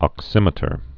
(ŏk-sĭmĭ-tər)